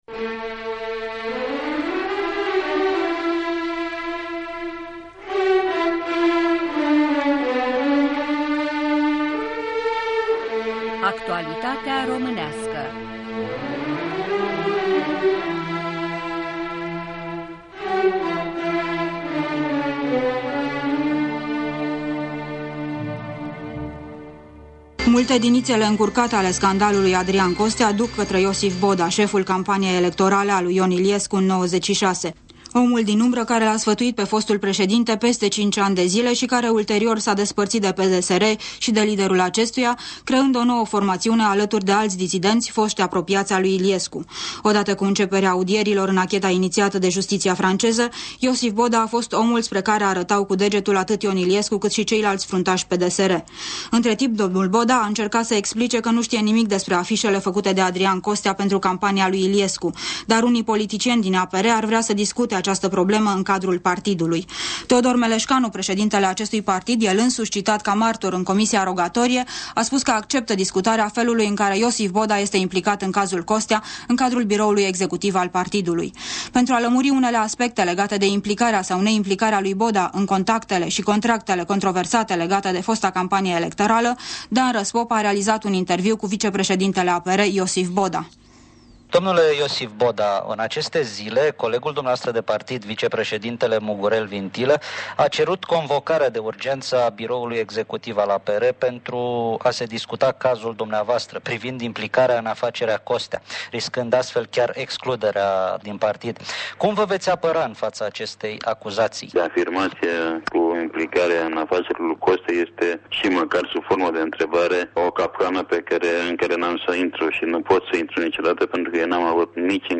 Un interviu cu Iosif Boda despre scandalul Adrian Costea